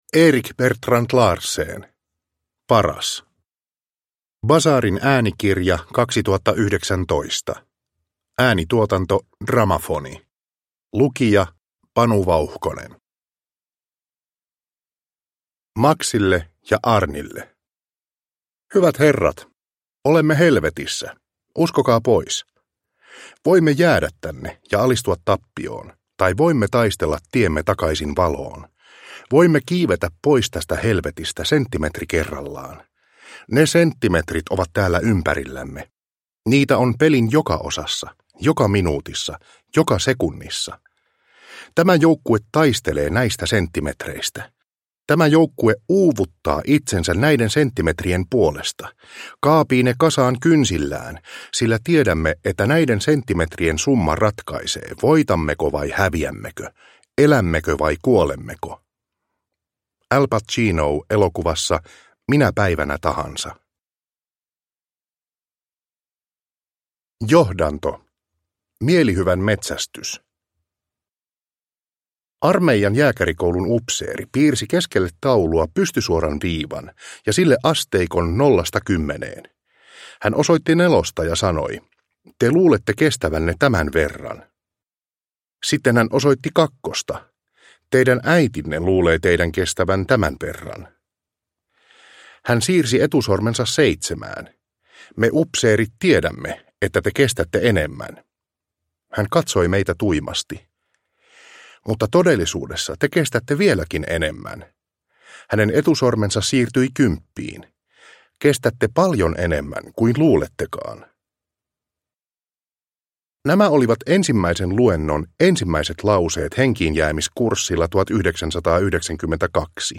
Paras – Ljudbok – Laddas ner